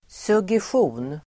Ladda ner uttalet
suggestion substantiv, suggestion Uttal: [sugesj'o:n] Böjningar: suggestionen Synonymer: förslag Definition: mental påverkan Sammansättningar: massuggestion (mass suggestion) suggestion substantiv, suggestion